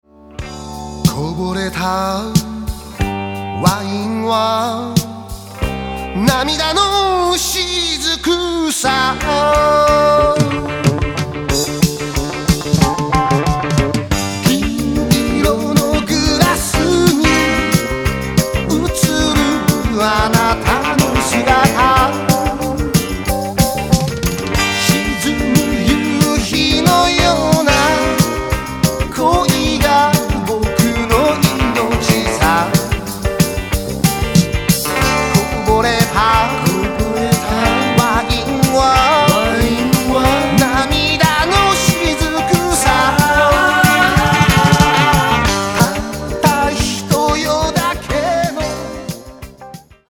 ジャンル：ロック